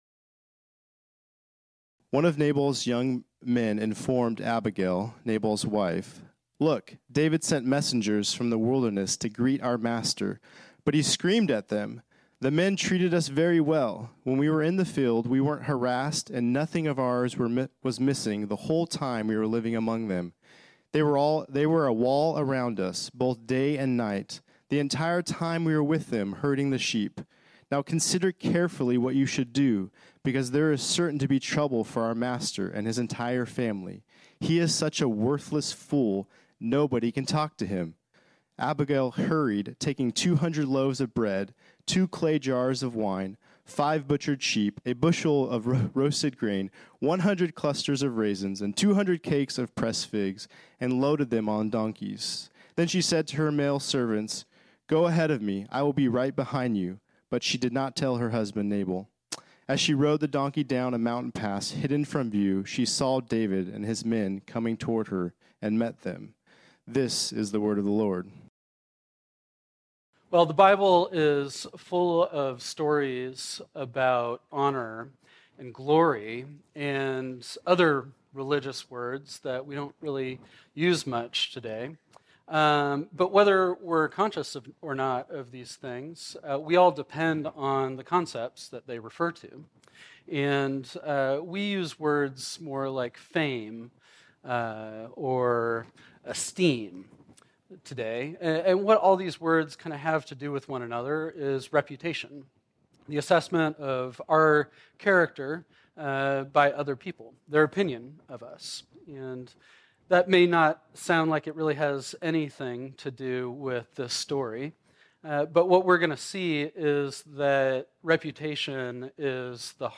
This sermon was originally preached on Sunday, June 18, 2023.